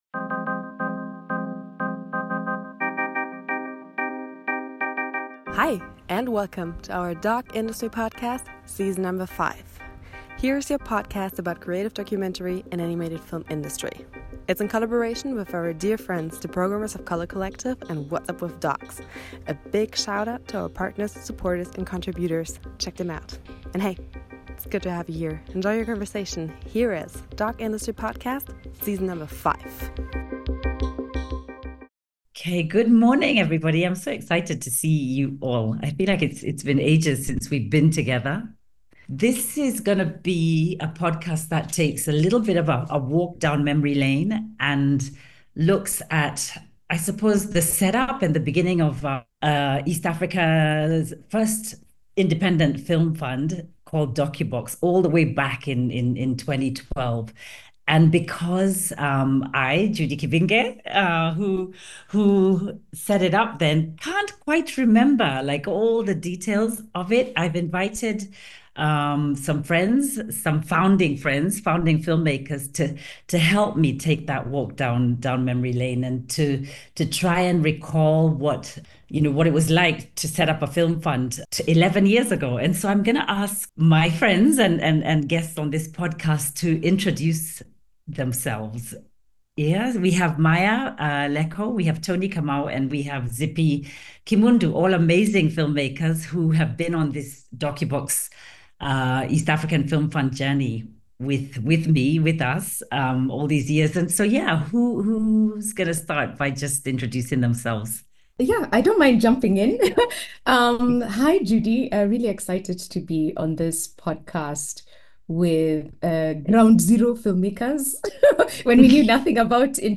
Through this lively and frank conversation, audiences will understand what a growing community of filmmakers can achieve.